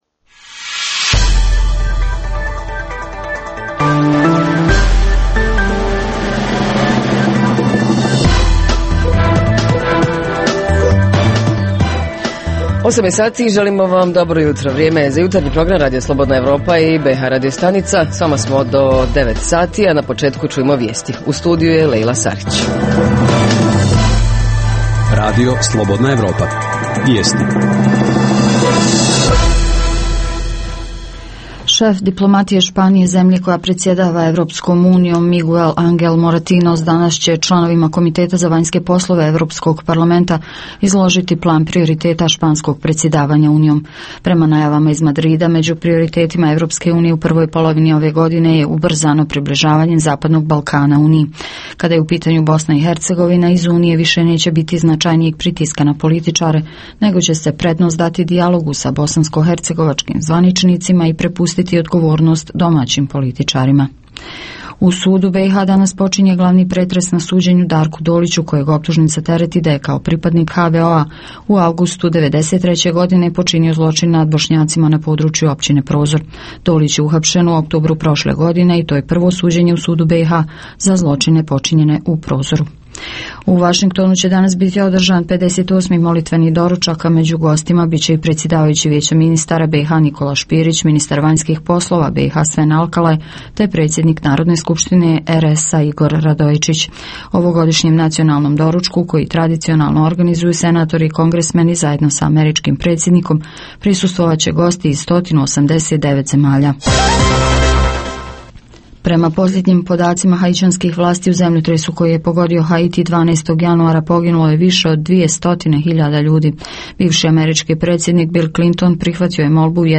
Jutarnji program ovog jutra pita: može li se danas živjeti od umjetnosti? Reporteri iz cijele BiH javljaju o najaktuelnijim događajima u njihovim sredinama.
Redovni sadržaji jutarnjeg programa za BiH su i vijesti i muzika.